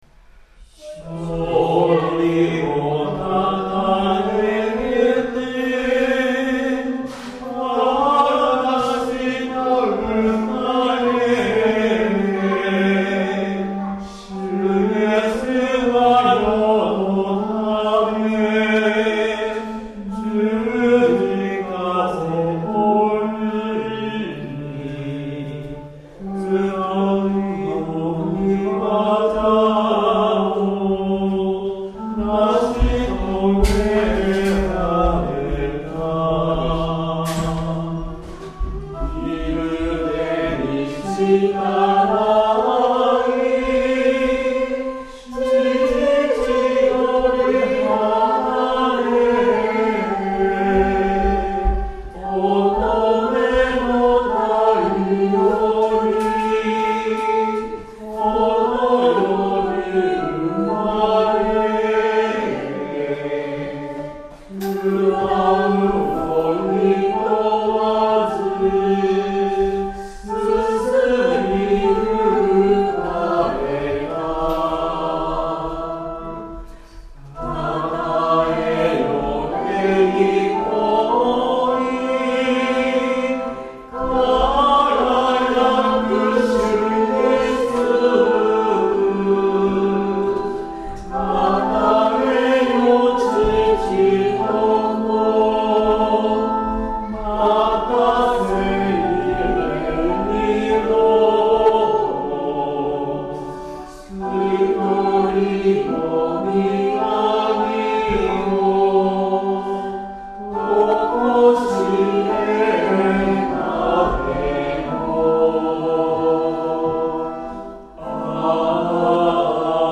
曲：単旋律聖歌 PANGE LINGUA
Temperament = Equal
Sound♪ リバーブ付 Middle Room